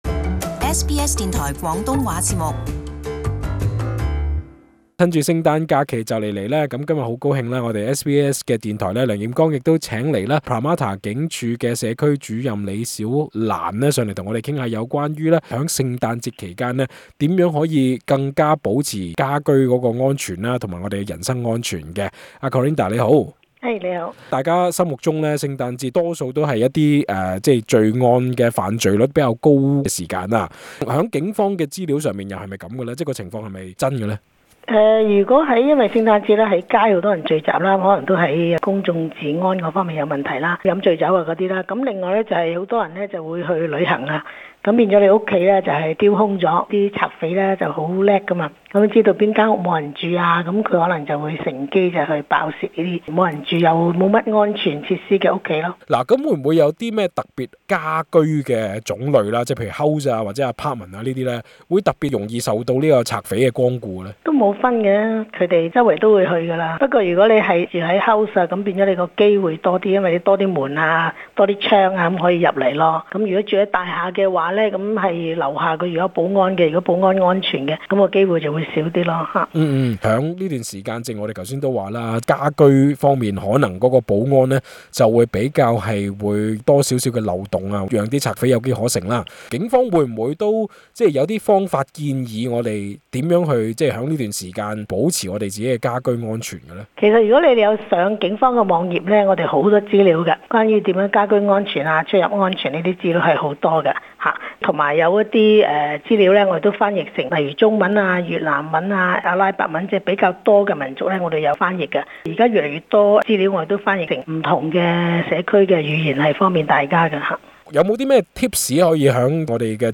【社区专访】圣诞期间如何保障自身及家居安全